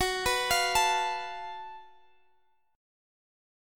GbmM7bb5 Chord
Listen to GbmM7bb5 strummed